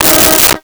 Car Horn 02
Car Horn 02.wav